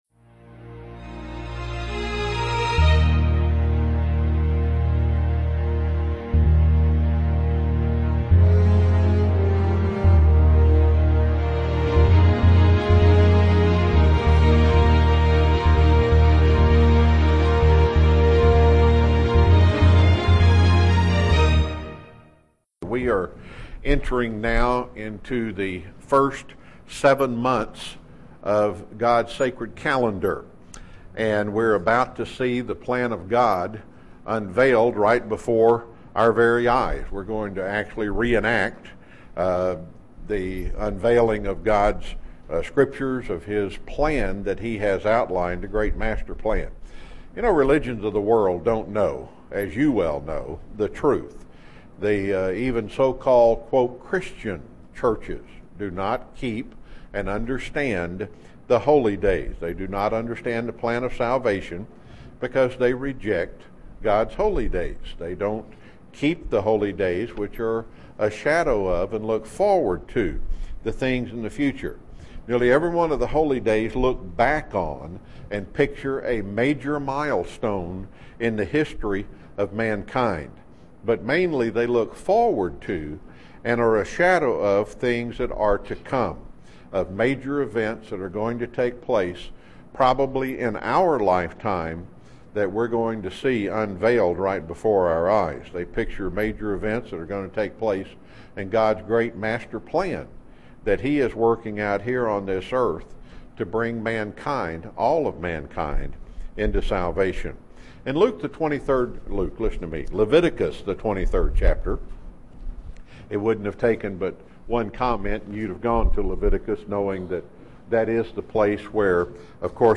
Given in Chattanooga, TN
Print How should we prepare for the Days of Unleavened Bread UCG Sermon Studying the bible?